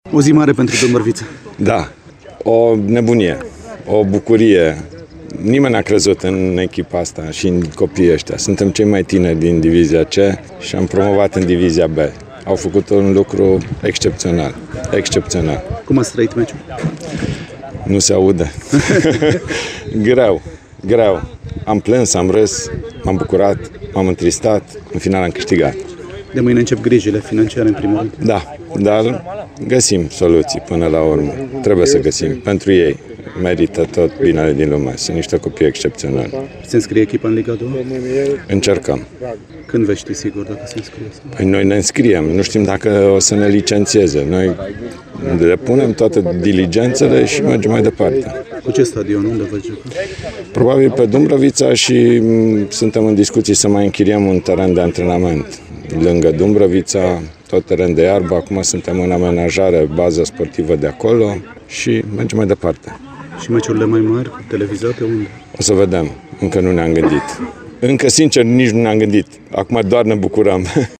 „O nebunie, o bucurie! Nimeni n-a crezut în echipa asta, în copiii aceștia. Suntem cei mai tineri din Liga a III-a și am reușit promovarea. E un lucru excepțional!”, a declarat, pentru Radio Timișoara, primarul Dumbrăviței, Horia Bugarin, imediat după finalul meciului din Valea Domanului.